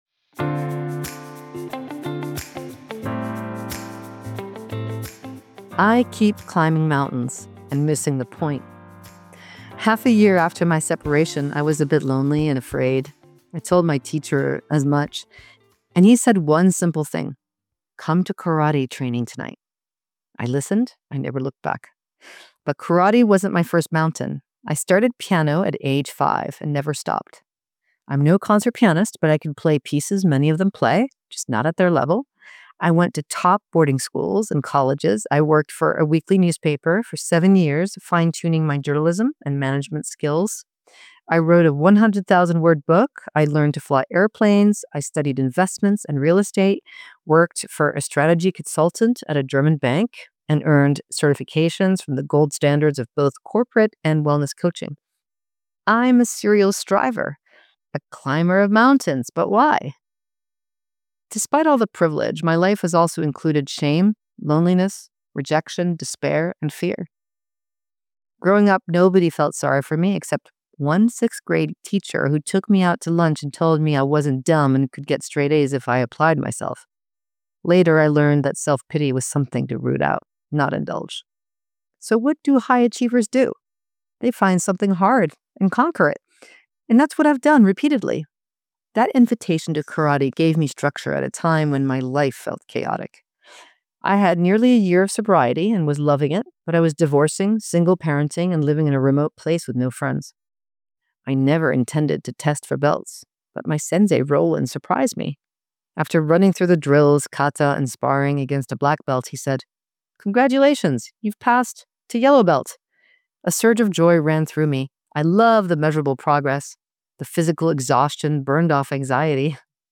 She stops.